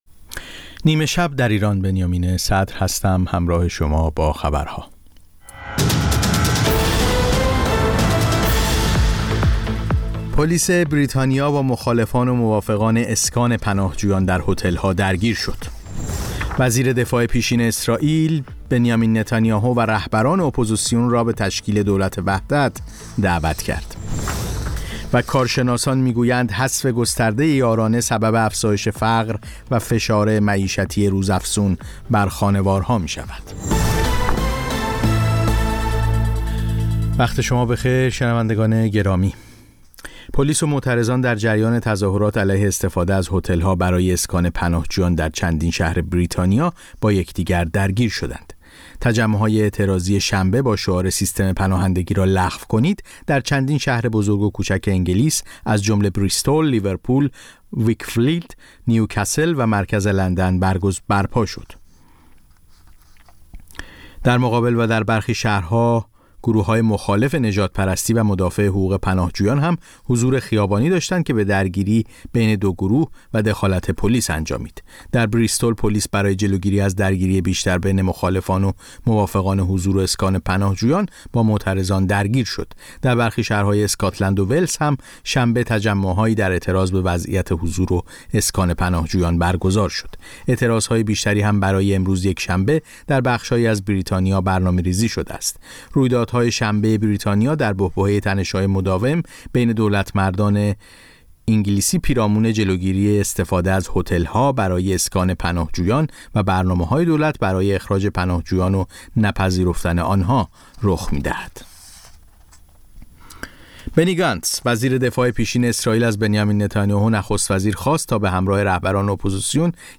سرخط خبرها ۰۰:۰۰